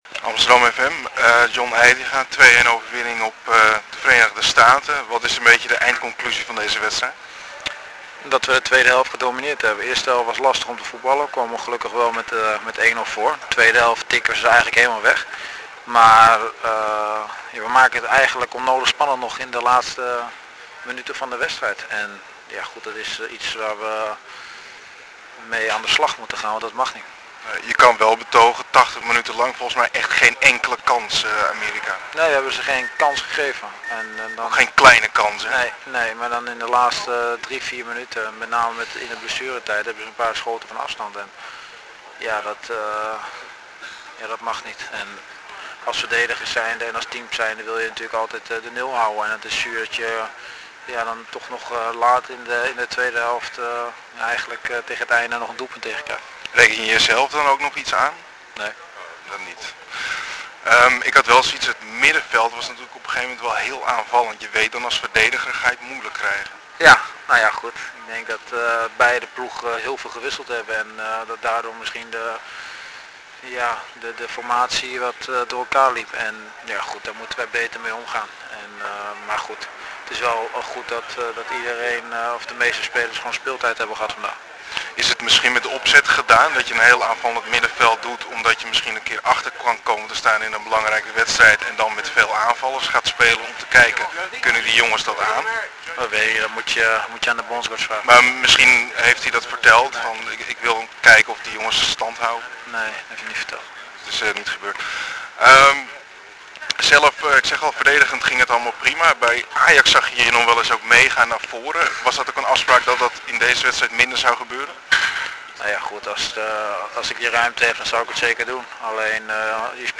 Interview met John Heitinga na de 6-1 overwinning op Hongarije in juni 2010: